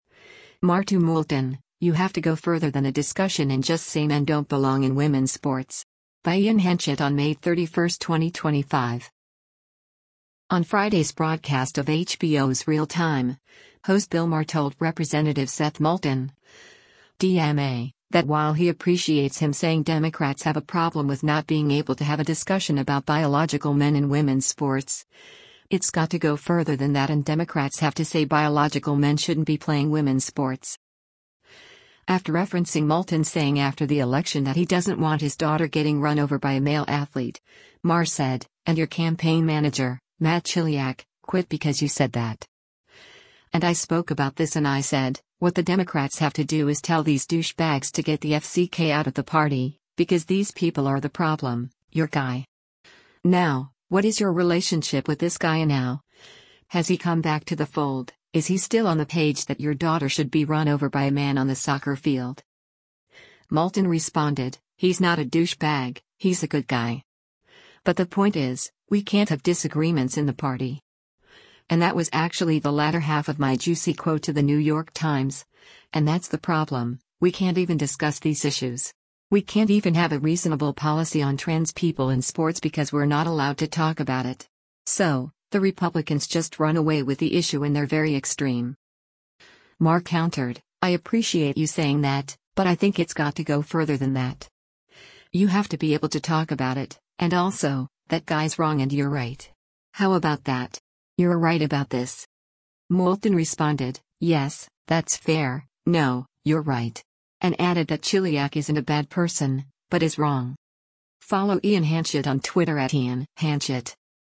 On Friday’s broadcast of HBO’s “Real Time,” host Bill Maher told Rep. Seth Moulton (D-MA) that while he appreciates him saying Democrats have a problem with not being able to have a discussion about biological men in women’s sports, “it’s got to go further than that” and Democrats have to say biological men shouldn’t be playing women’s sports.